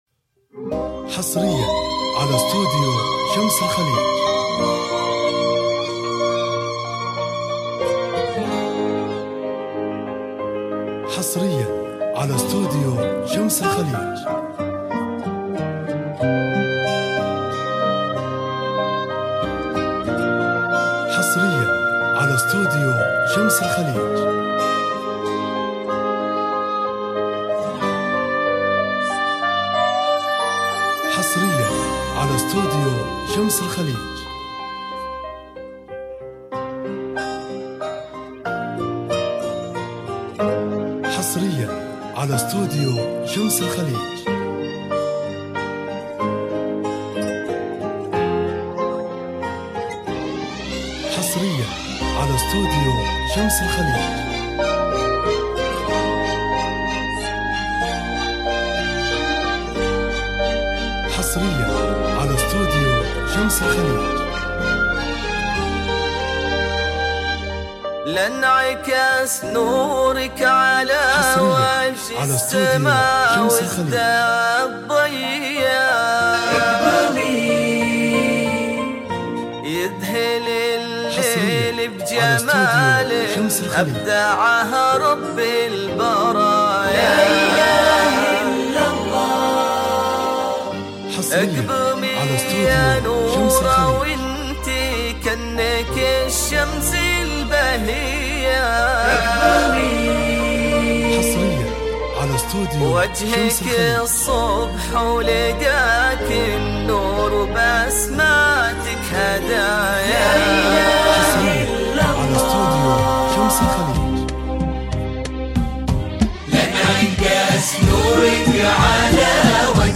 زفة معرس موسيقية حصرية وفخمة